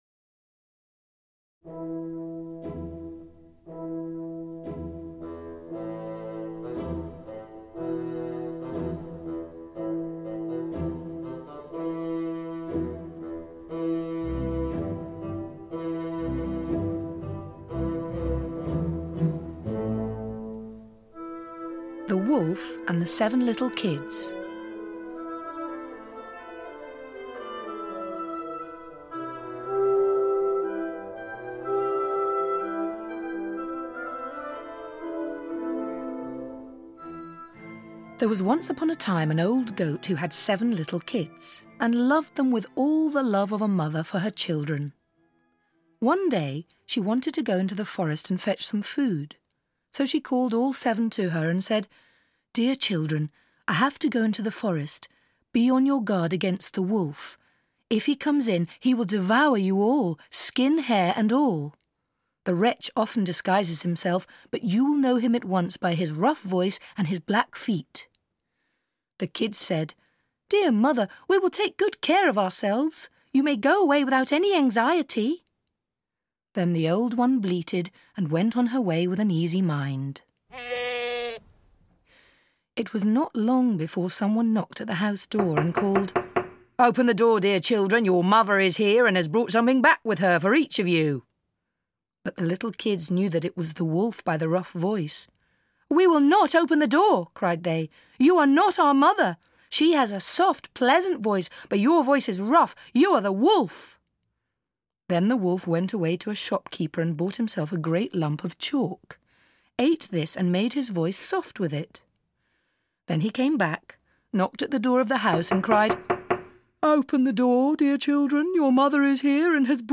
Audio knihaGrimms’ Fairy Tales – Volume 2 (EN)
Ukázka z knihy